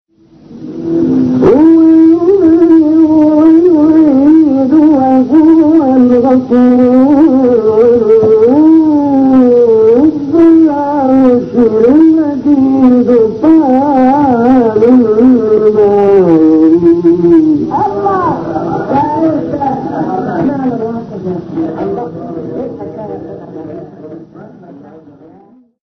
گروه شبکه اجتماعی: مقاطع صوتی از تلاوت قاریان بنام و برجسته جهان اسلام که در شبکه‌های اجتماعی منتشر شده است، می‌شنوید.